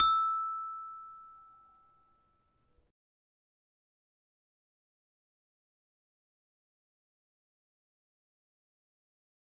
XyloE.ogg